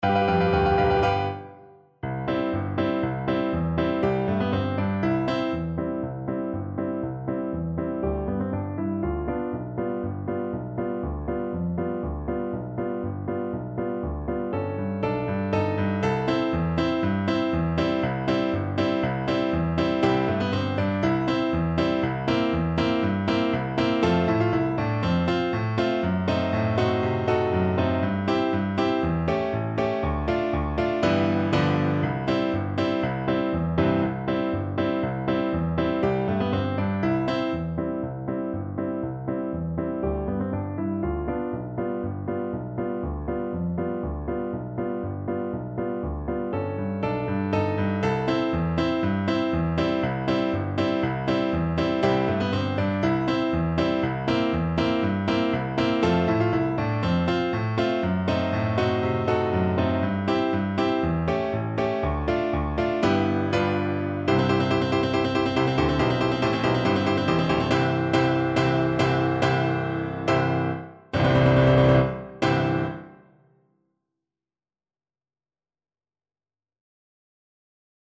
2/4 (View more 2/4 Music)
Tempo di Marcia =120
Classical (View more Classical Tenor Saxophone Music)